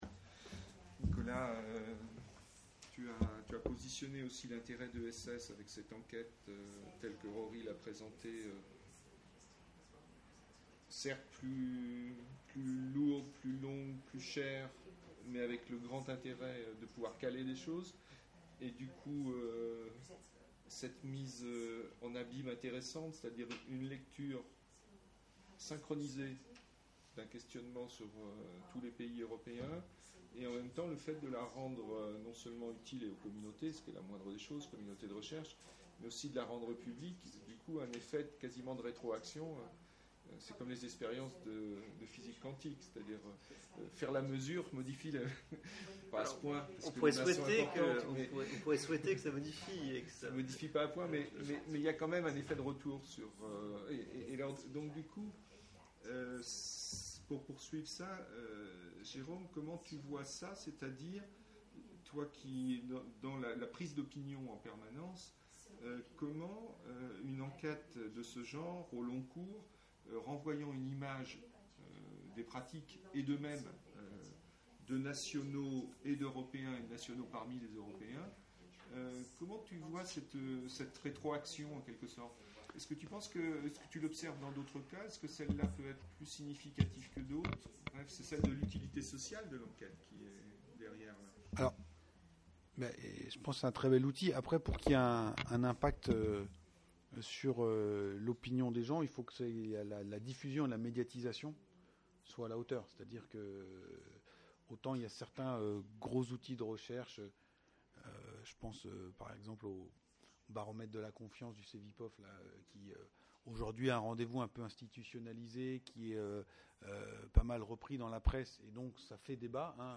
ESS Topline results presentation - Europeans and democracy - Question (4/4) : rétroaction, médiatisation des données et spécificité de l'enquête | Canal U